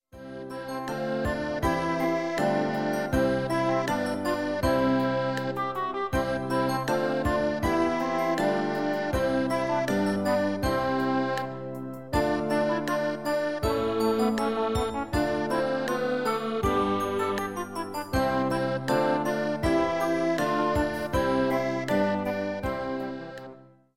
ACDLL-C-Major-Clip.mp3